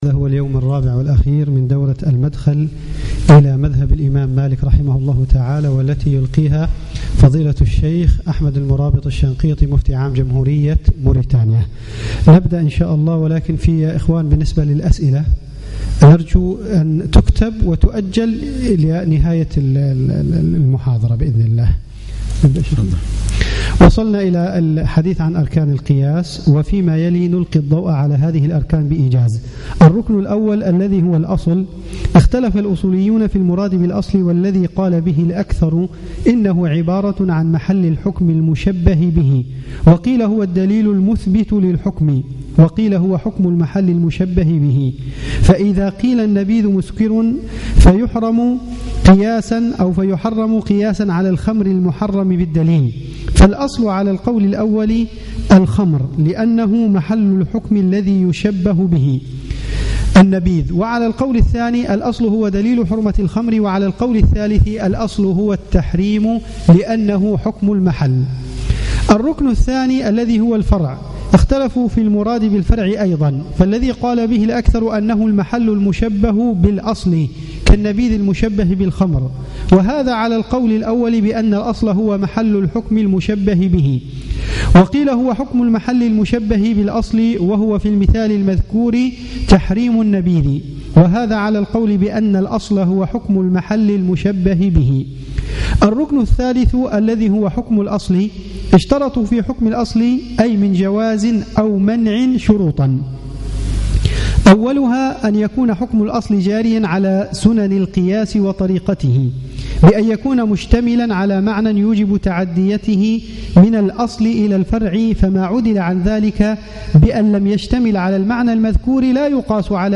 صباح الخميس 23 جمادى الأولى 1437 الموافق 3 3 2016 بمبني تدريب الأئمة والمؤذنين
الدرس السابع